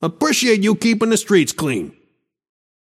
Shopkeeper voice line - Appreciate you keepin‘ the streets clean.